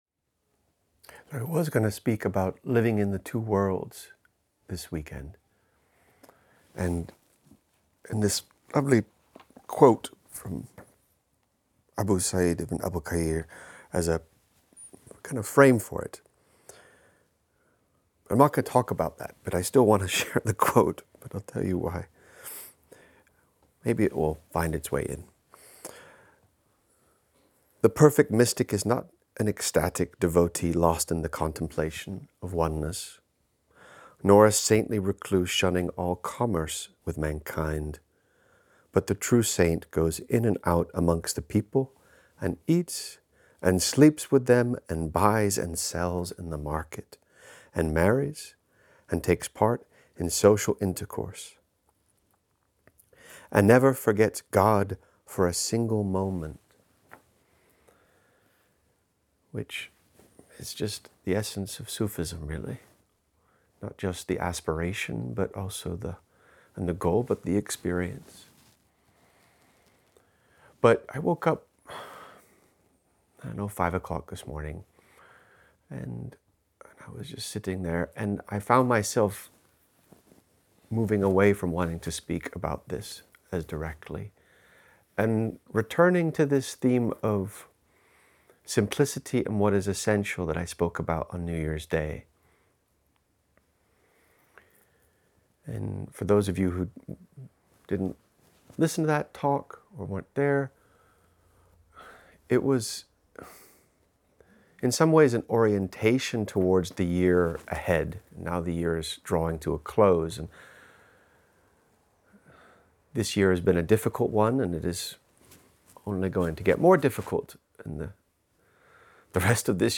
November London Seminar